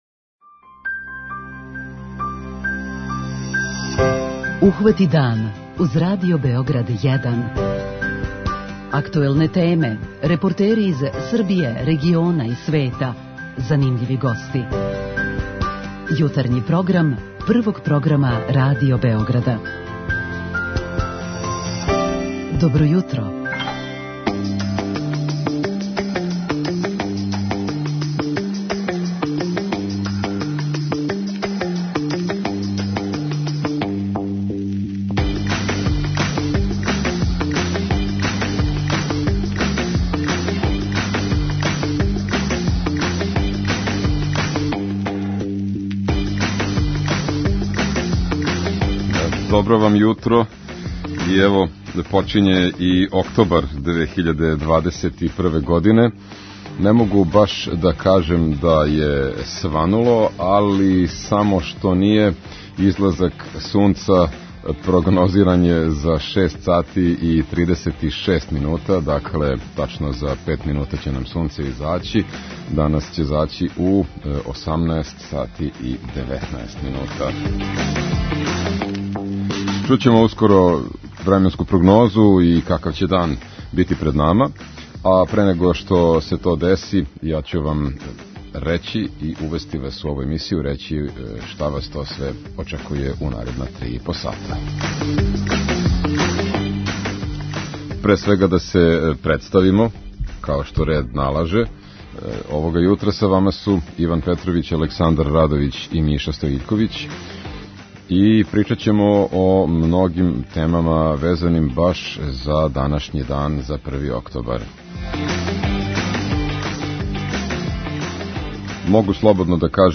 Рођендан, наравно, прослављамо радно, али ћемо се мало опустити у ћаскању са слушаоцима у оквиру наше редовне рубрике 'Питање јутра'.
У нашој земљи тим поводом биће покренута једна кампања, а више о њој, као и о положају и дискриминацији старијих људи, говориће нам повереница за равноправност Бранкица Јанковић која ће нам се укључити у програм.